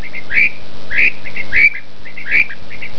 Hear the female quail call (wav-file below 50kB).
quailf4.wav